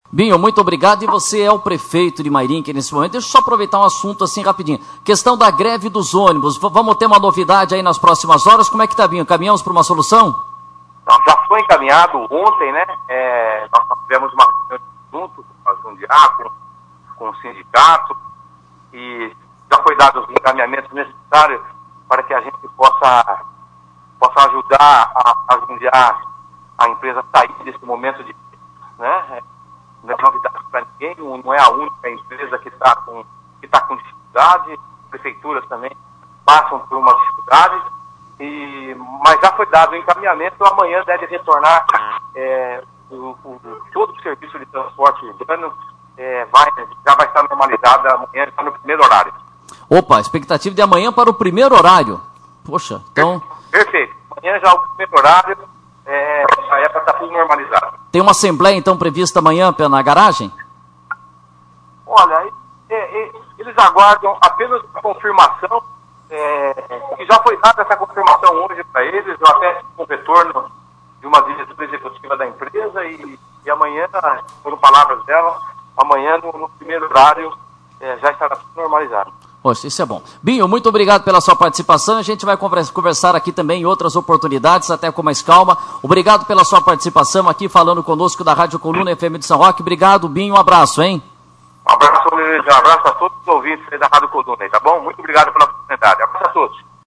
Na tarde de terça-feira (25), o prefeito Binho Merguizo disse durante entrevista ao programa Linha Aberta da Rádio Coluna que a situação estava “encaminhada” e que os ônibus voltariam a circular no primeiro horário desta quarta-feira.